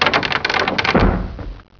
doorClose.wav